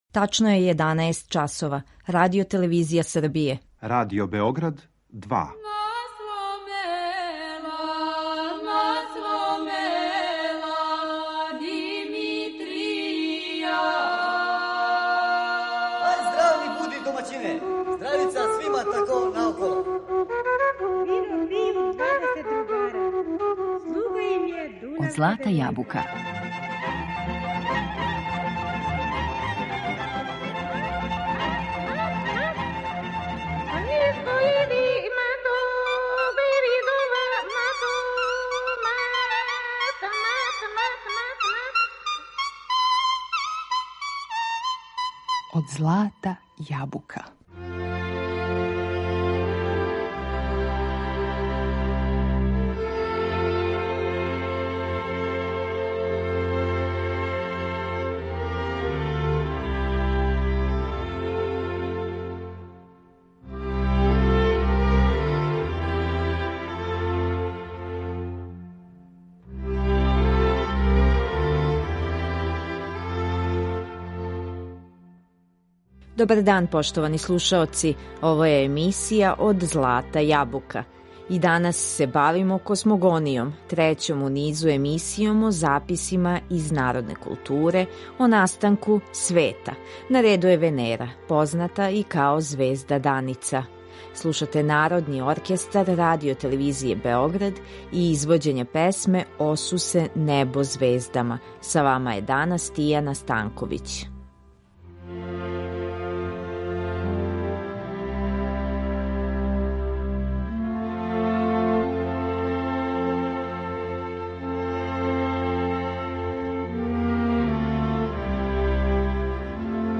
У данашњем издању емисије Од злата јабука по трећи пут говоримо о космогонији у народној култури. Тема је звезда Даница у записима етнолога и снимцима народних песама.